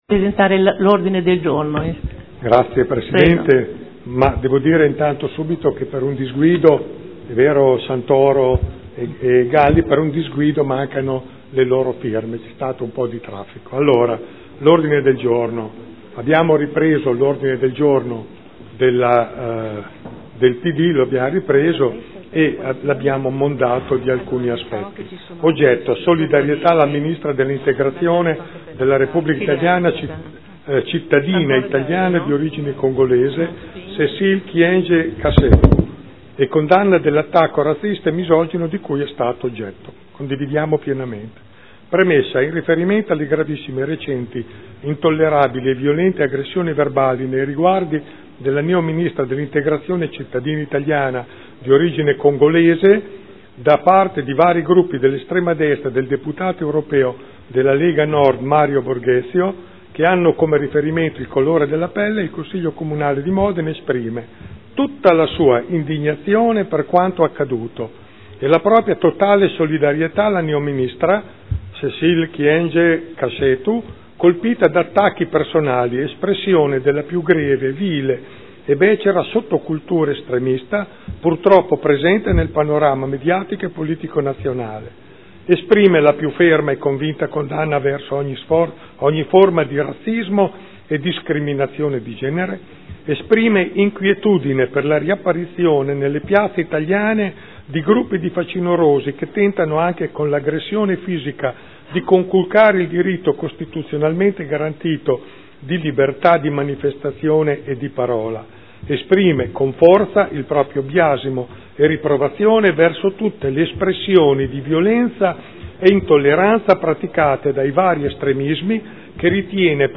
Giancarlo Pellacani — Sito Audio Consiglio Comunale
Seduta del 13/05/2013 Ordine del Giorno 58832